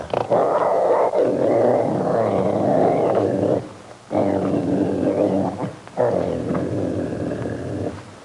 Growling Bear Sound Effect
Download a high-quality growling bear sound effect.
growling-bear-1.mp3